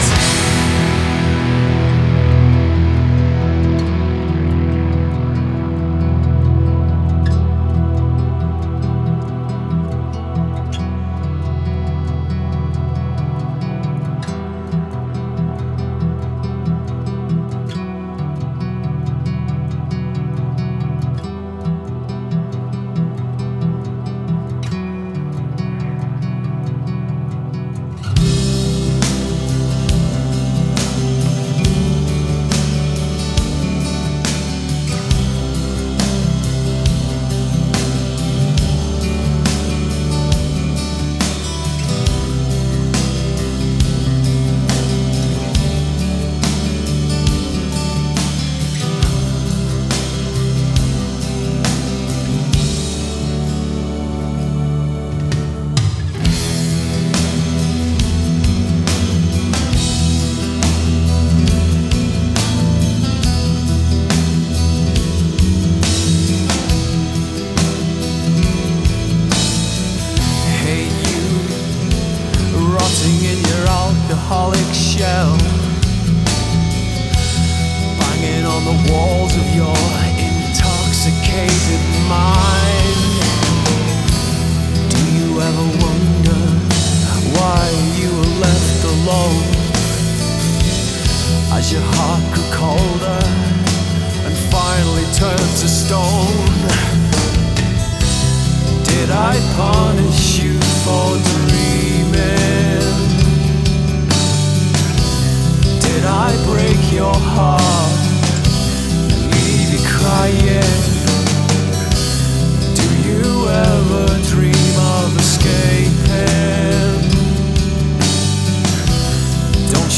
progressive metal Alternative/Indie Rock